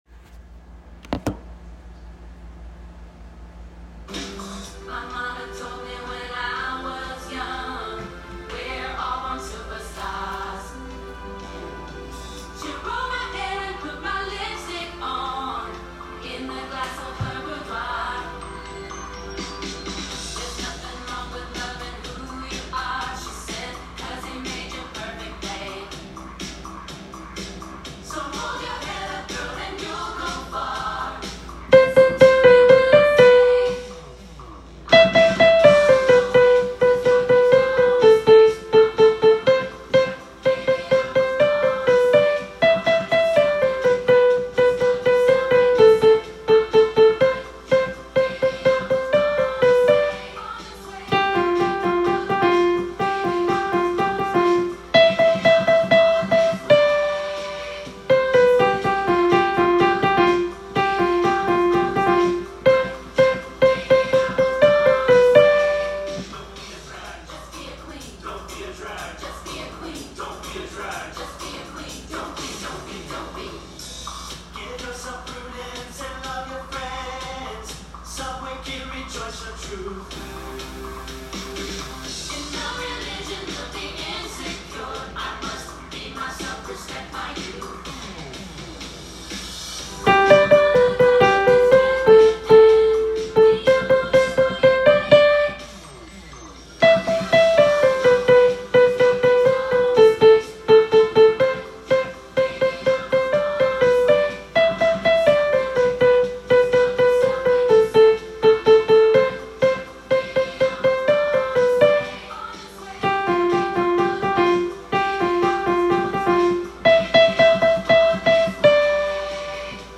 Soprano Part Track